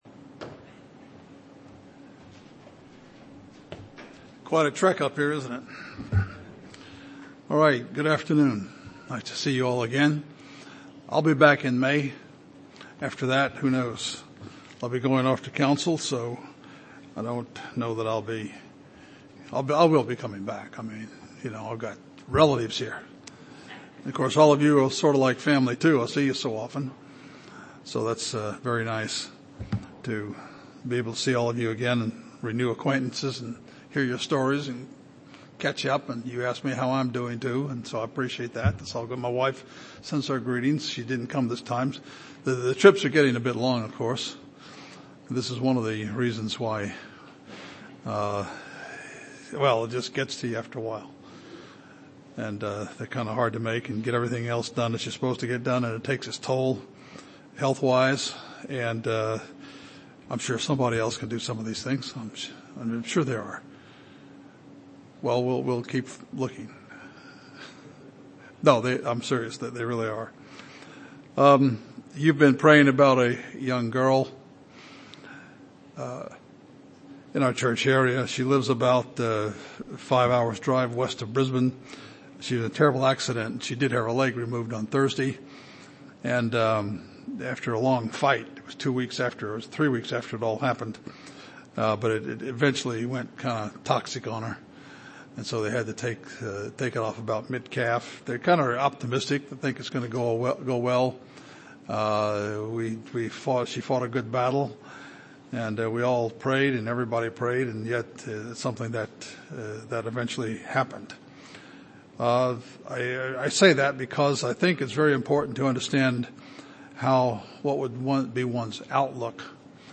Sermons
Given in Chicago, IL